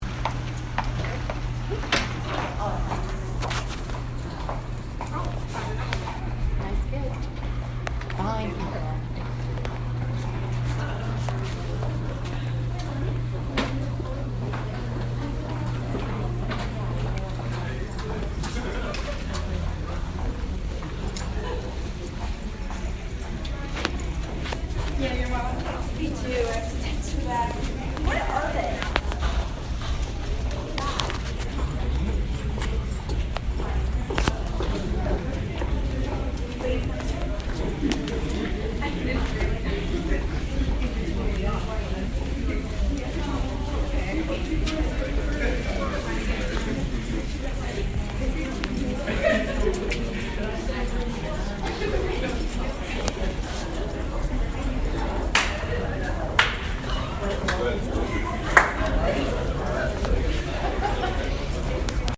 Sound Description: Small conversations, heels walking on the ground, clapping, and footsteps as people walk across the unispan.